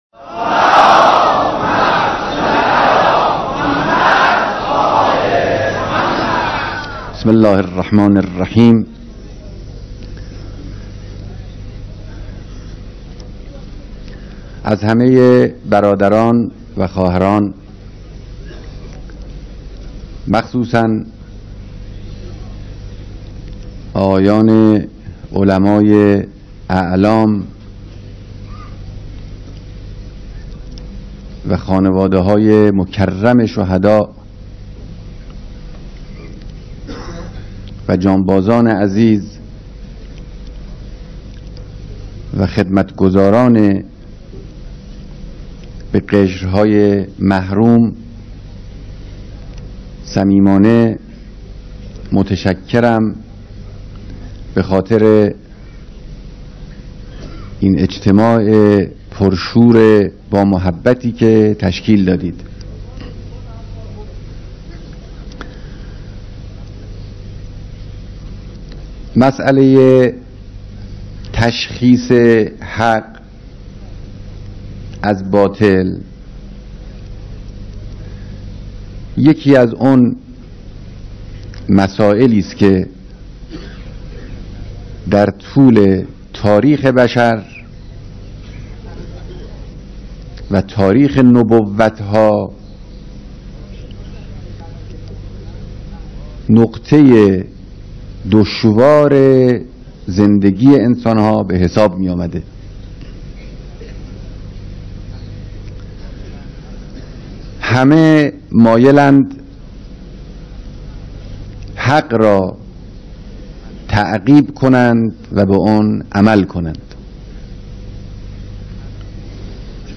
یانات رهبر انقلاب در دیدار جمعی از مسئولان نهادها و سازمان‌های خدماتی و حمایت‌کننده